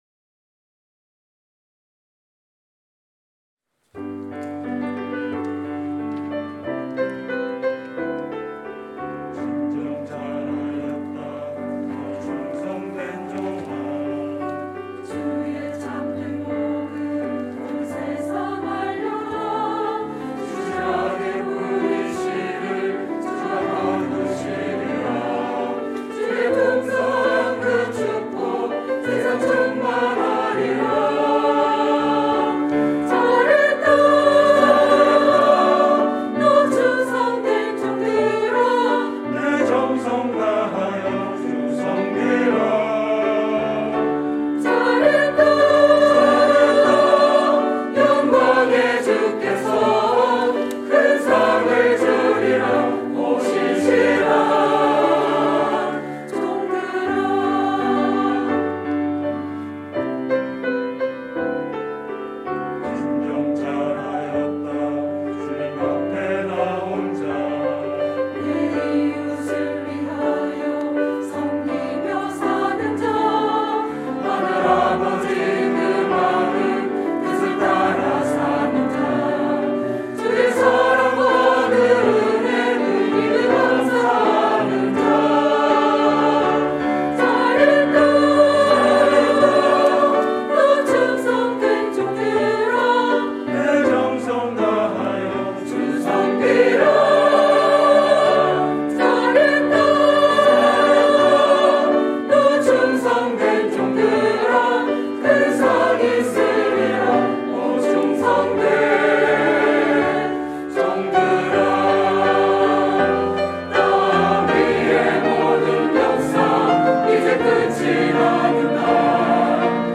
갈릴리